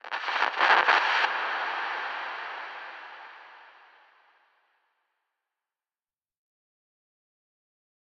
Minecraft Version Minecraft Version latest Latest Release | Latest Snapshot latest / assets / minecraft / sounds / ambient / nether / warped_forest / addition3.ogg Compare With Compare With Latest Release | Latest Snapshot